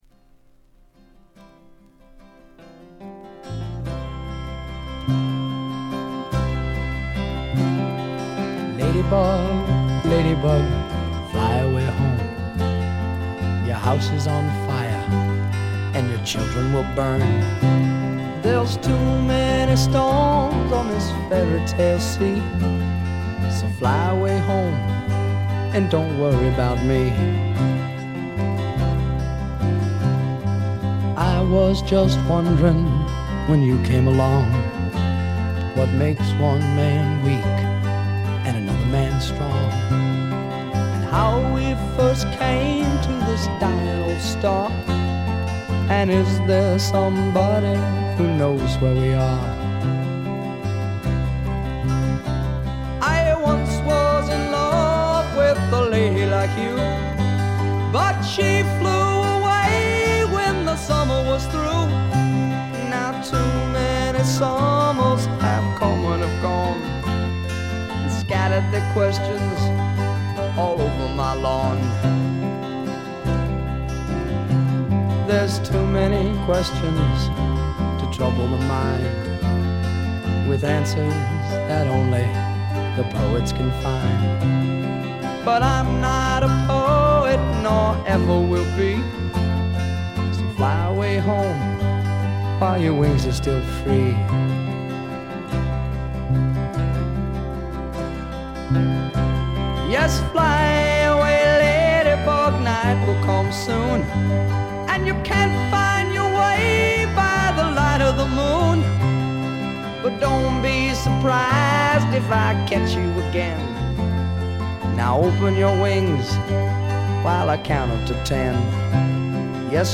ほとんどノイズ感無し。
試聴曲は現品からの取り込み音源です。
Vocals, Guitar